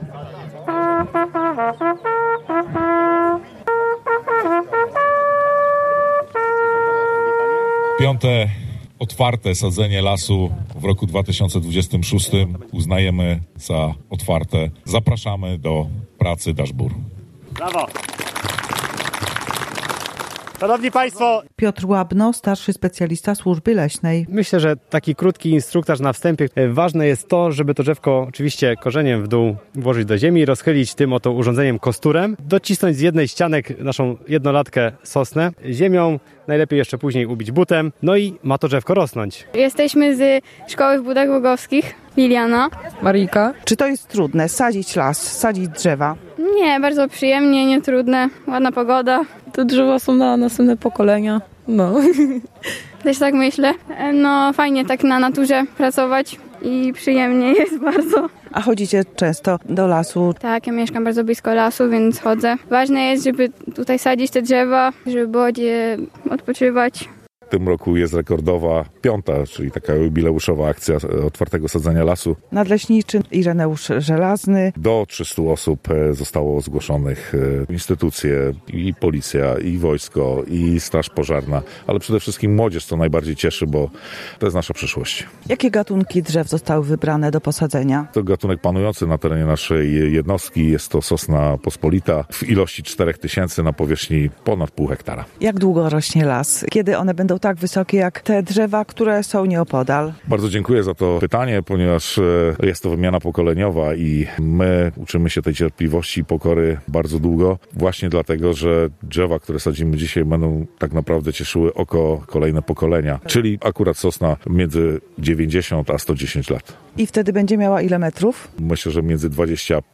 Wiadomości • Około trzystu miłośników przyrody wzięło udział w akcji sadzenia drzew (16.04) w leśnictwie Bratkowice.